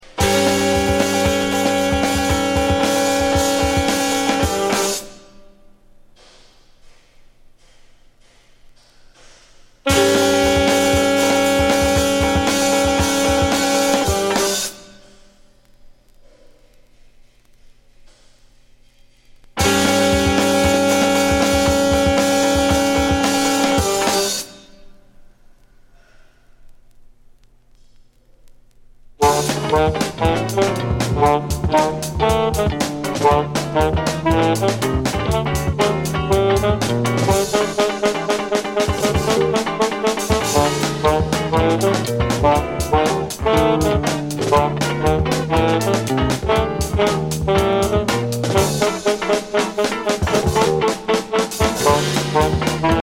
アルトサックス
ギター、ベース、ピアノ
ドラム